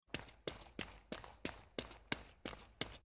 fox_running.mp3